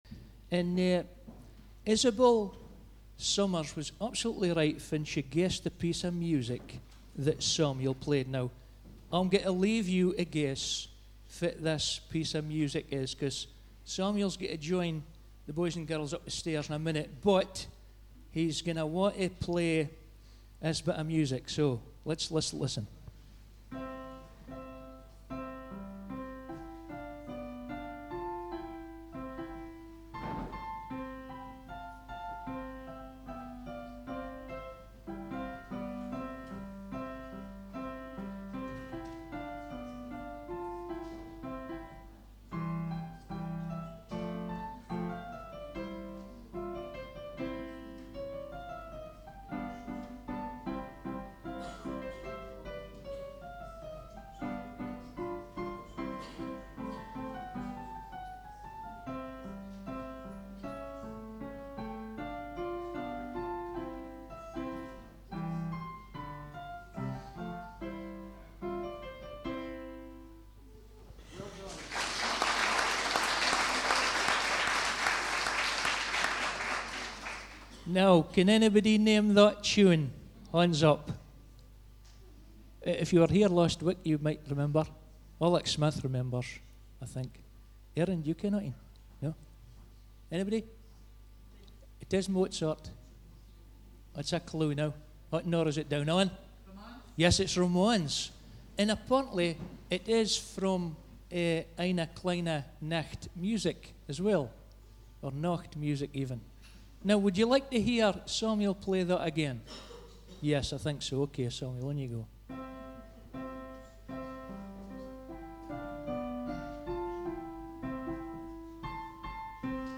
Christmas Eve Service 2017 – Fraserburgh Baptist Church